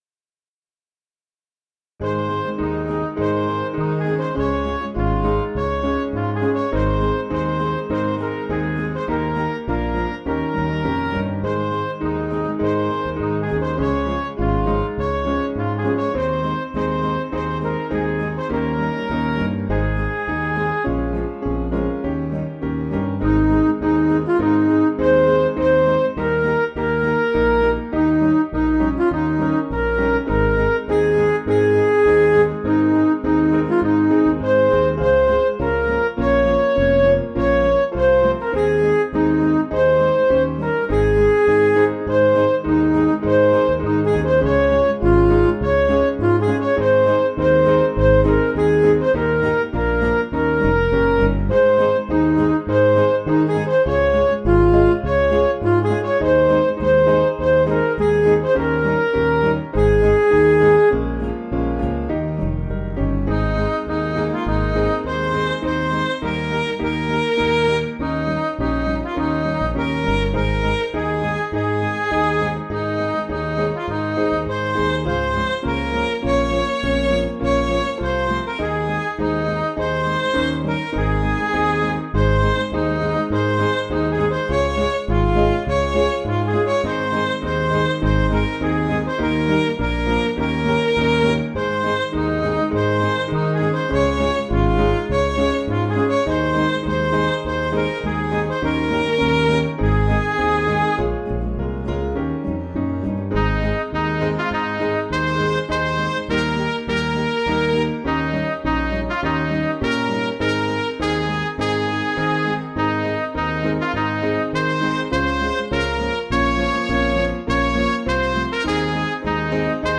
Piano & Instrumental
(CM)   3/Ab
Midi